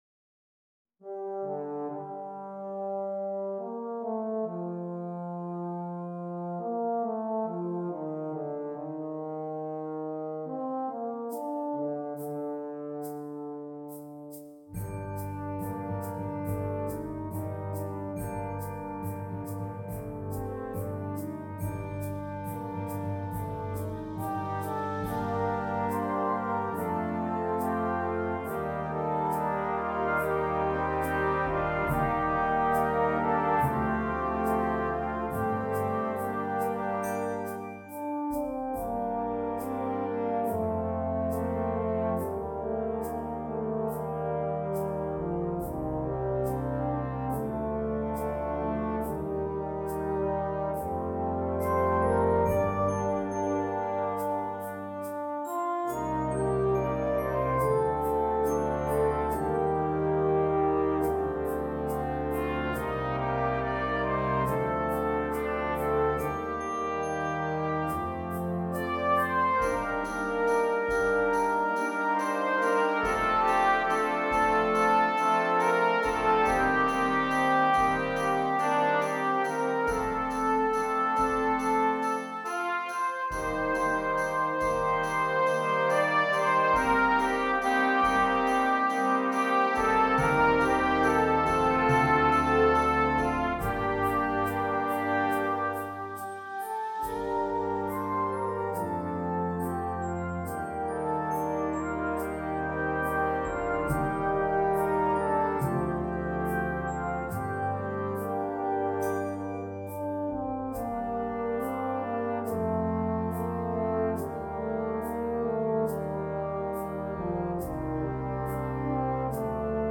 Voicing: Brass Choir